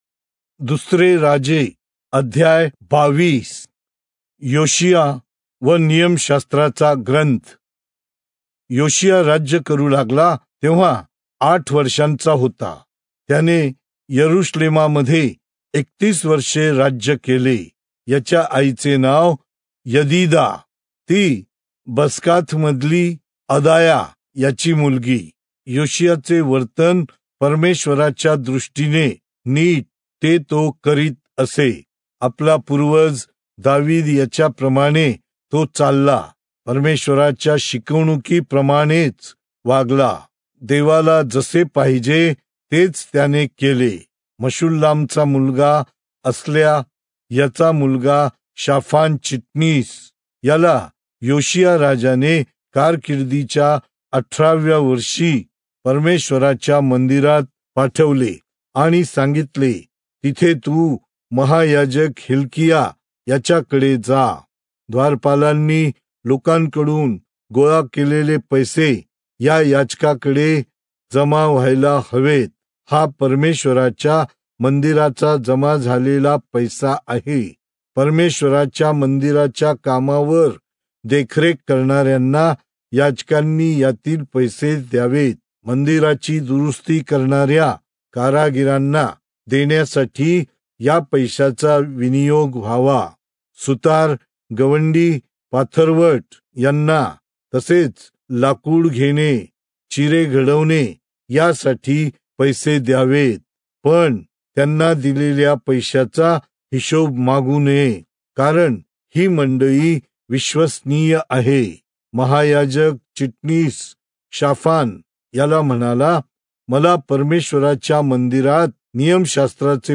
Marathi Audio Bible - 2-Kings 22 in Irvmr bible version